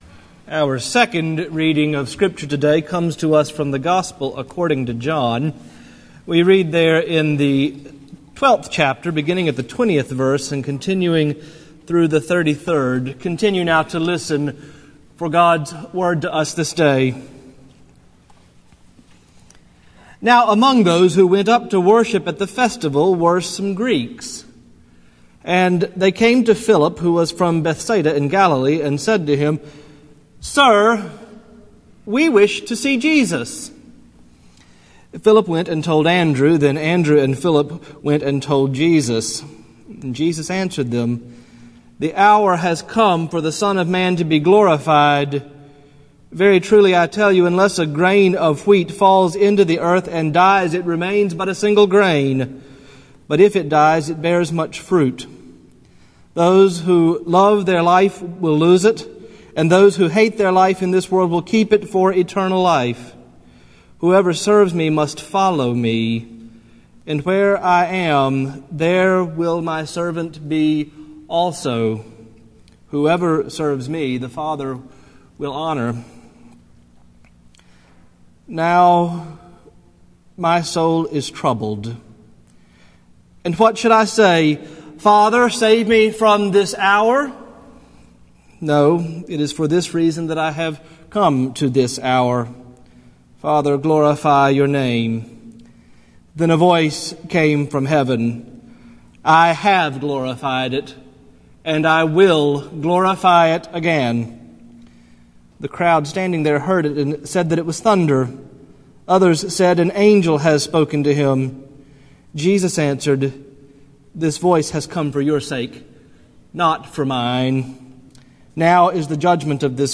sermon archive 2012 | Morningside Presbyterian Church